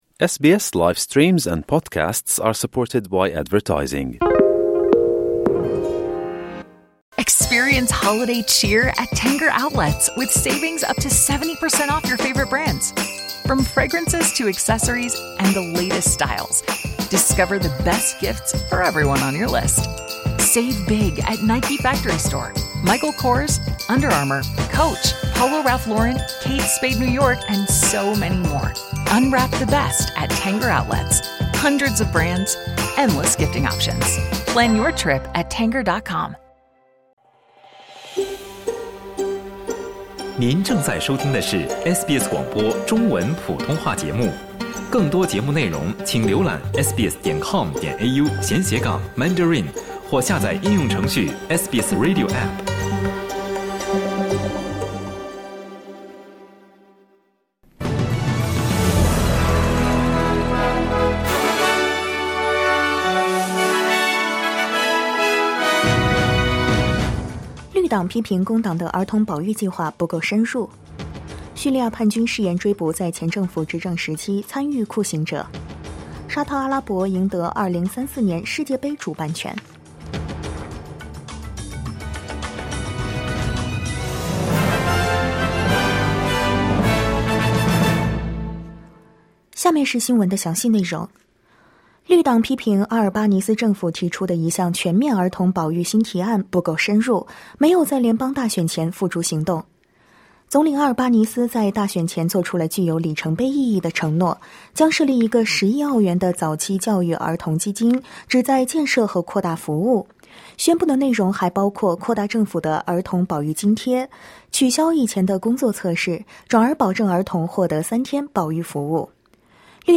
SBS早新闻（2024年12月12日）